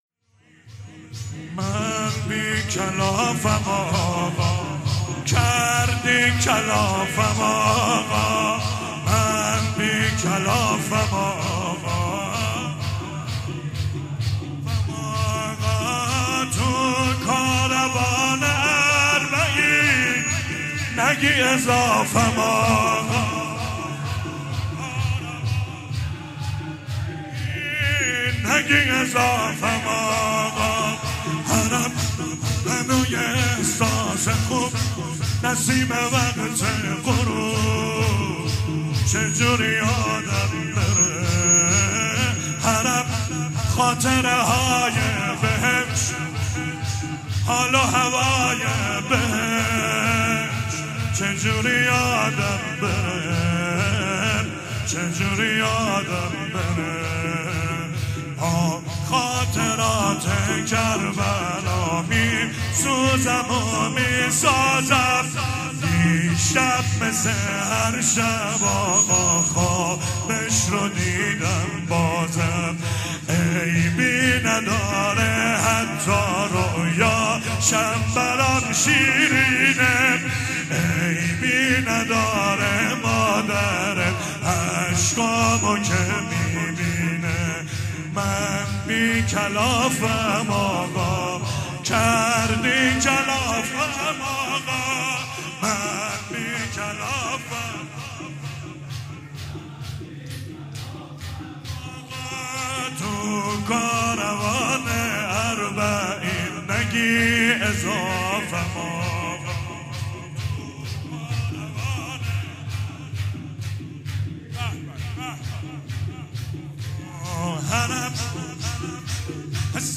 شهادت امام صادق علیه السلام